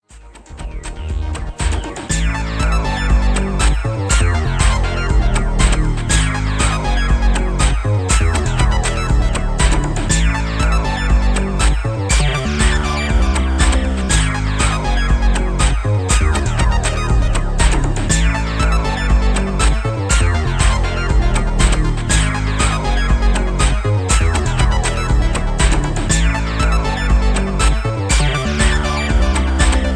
Nervous Techno Mix